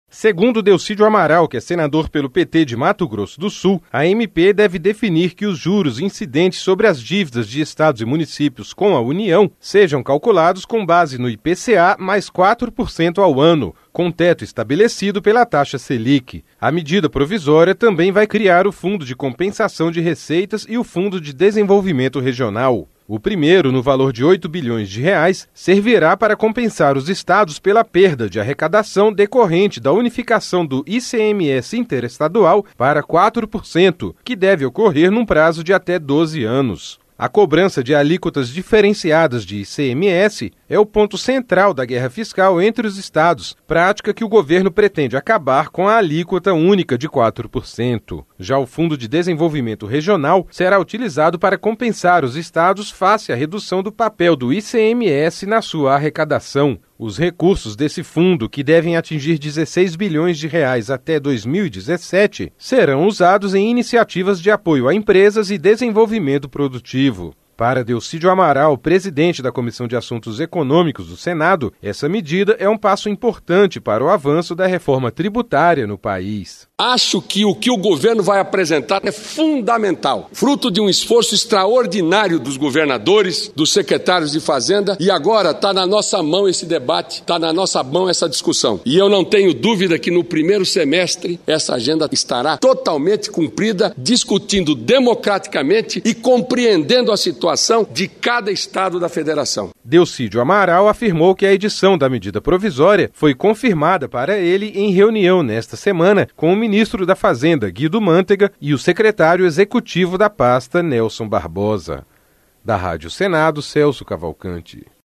LOC: FOI O QUE INFORMOU O SENADOR DELCIDIO DO AMARAL, PRESIDENTE DA COMISSÃO DE ASSUNTOS ECONÔMICOS, EM DISCURSO NA TRIBUNA DO SENADO NESTA QUINTA-FEIRA.